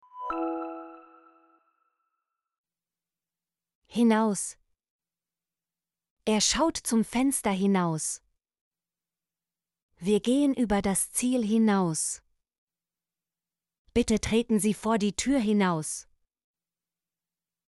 hinaus - Example Sentences & Pronunciation, German Frequency List